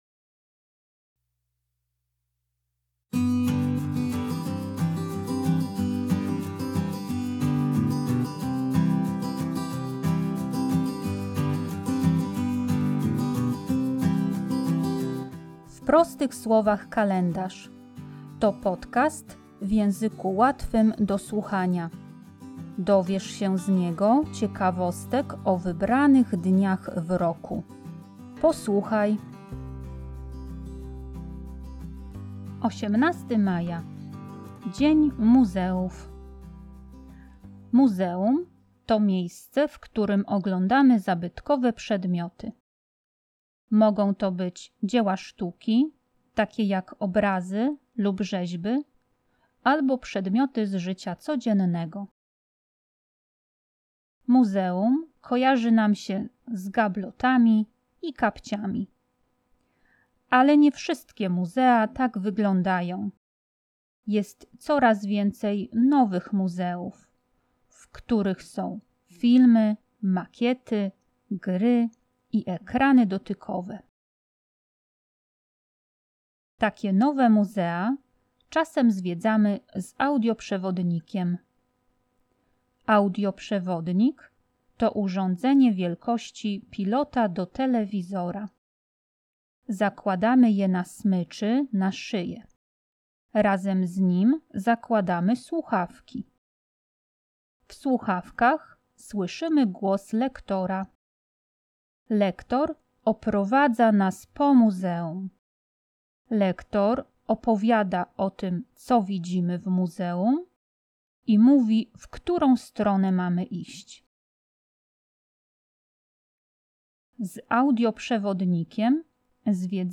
Podcasty w języku łatwym do słuchania - odcinek 5